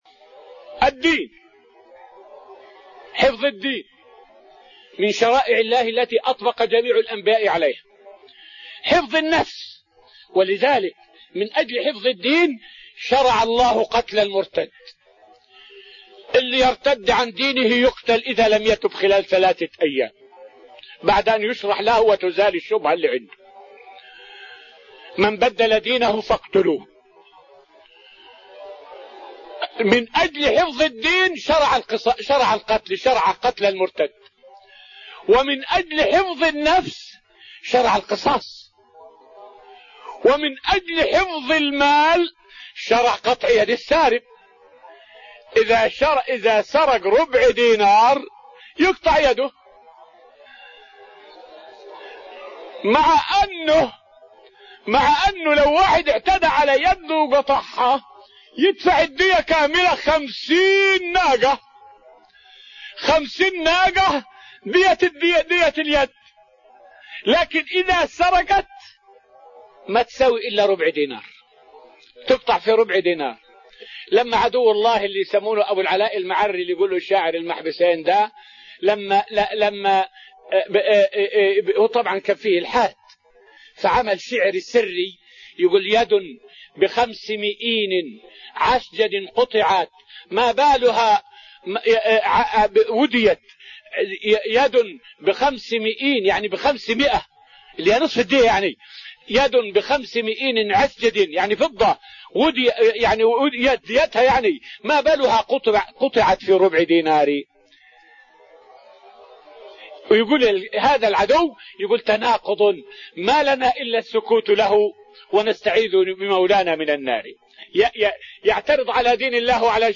فائدة من الدرس الثالث من دروس تفسير سورة الأنفال والتي ألقيت في رحاب المسجد النبوي حول تشريع الحدود لحفظ الكليات الخمس.